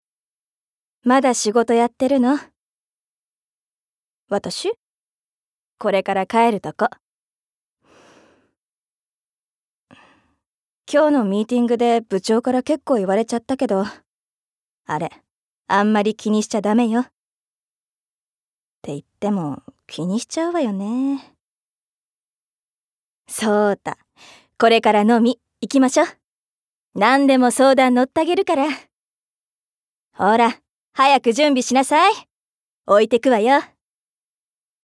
ボイスサンプル
セリフ３